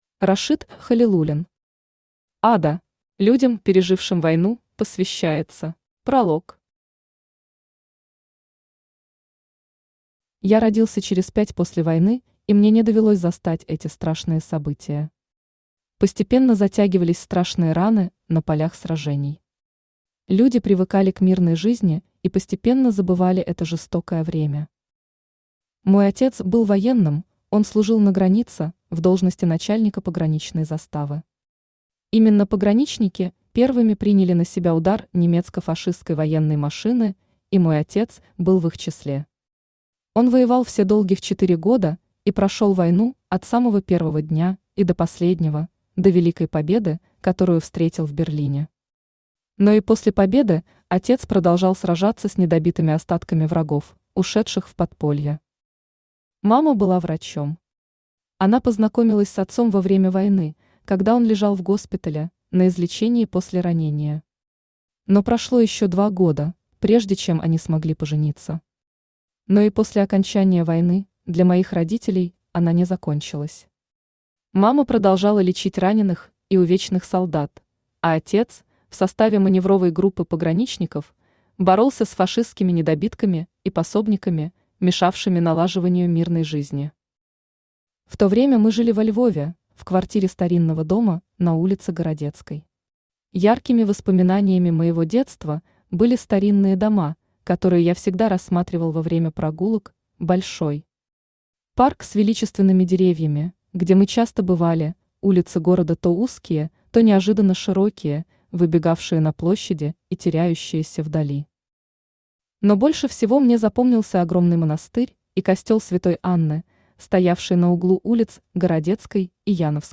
Aудиокнига Ада Автор Рашит Халилуллин Читает аудиокнигу Авточтец ЛитРес.